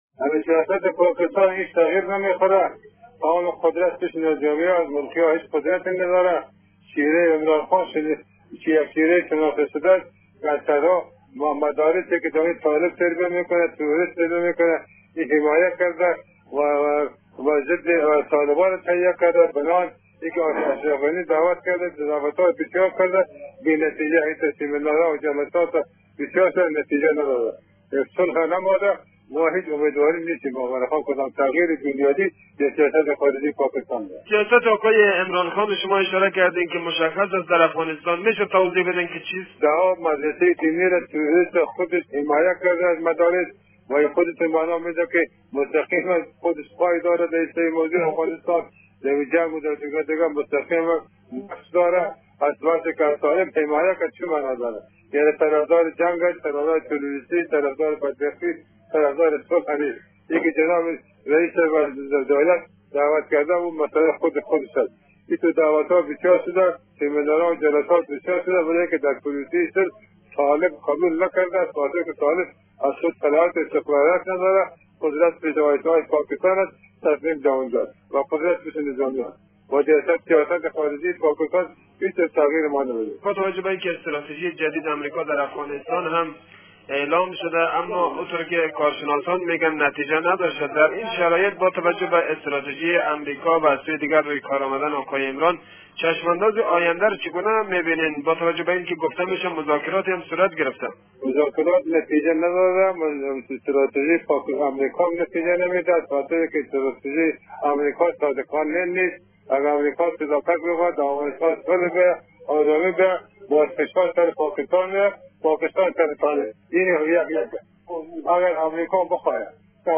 محمد سرور عثمانی فراهی نماینده پارلمان افغانستان در مصاحبه با برنامه انعکاس رادیو دری ضمن بیان این مطلب افزود: «عمران خان» رهبر حزب تحریک انصاف از طالبان حمایت می کند لذا اصلا امیدواری وجود ندارد که وی تغییری بنیادی در سیاست پاکستان به وجود بیاورد .